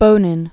(bōnĭn)